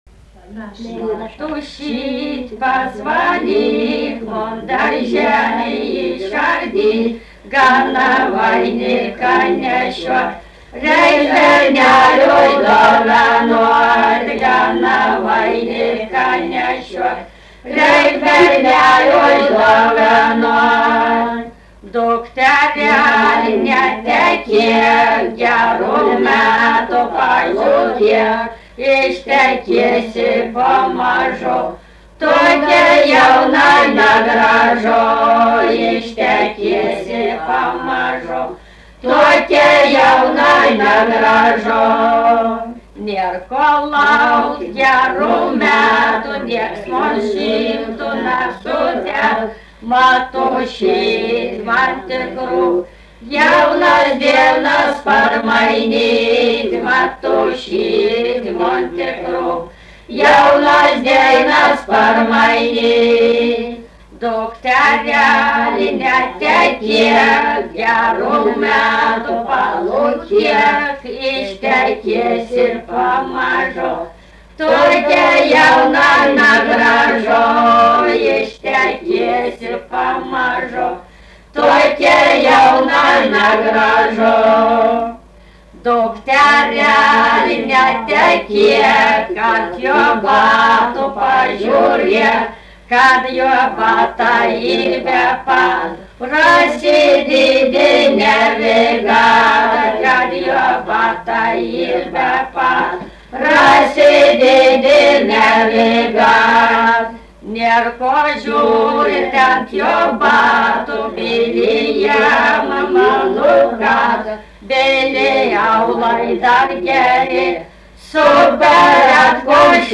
daina
Erdvinė aprėptis Luokė
Atlikimo pubūdis vokalinis
Pastabos 2 balsai